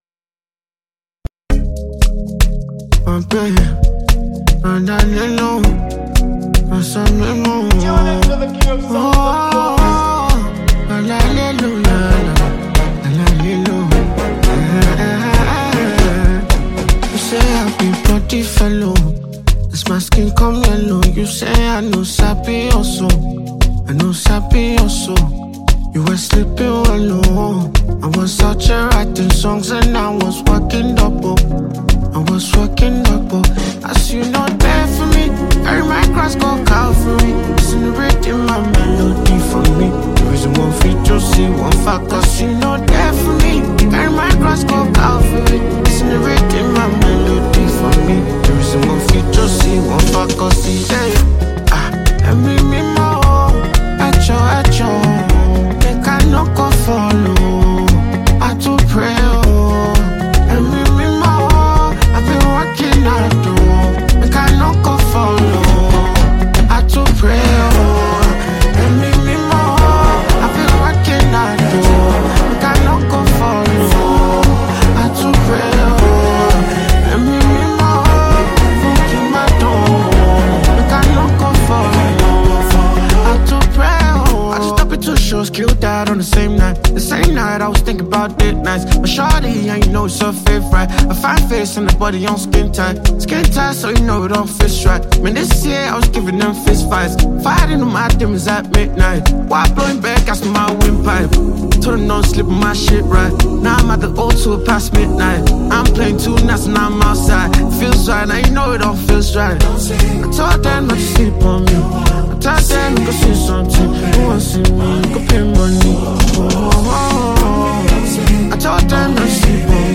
catchy song that has multiple verses and a relatable hook